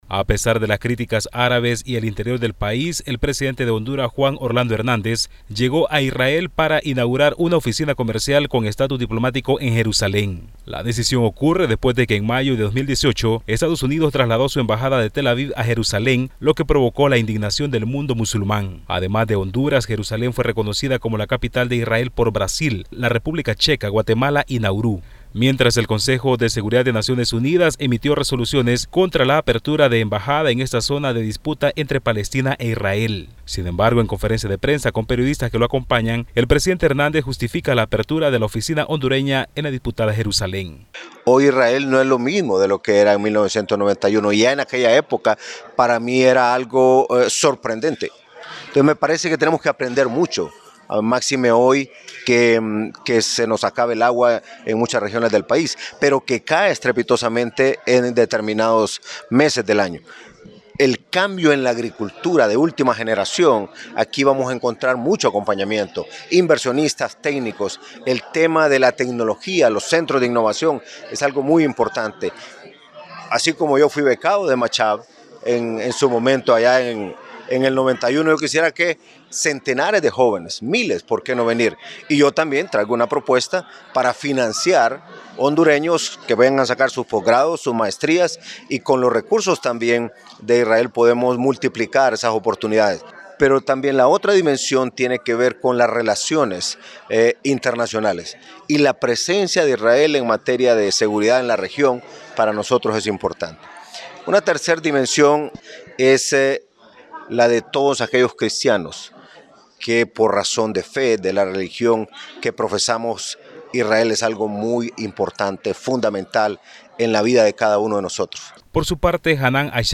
El presidente hondureño, Juan Orlando Hernández, inauguró en Jerusalén la Oficina de Comercio y Cooperación de Honduras en esta ciudad en presencia del primer ministro israelí, Benjamín Netanyahu. Escucha el informe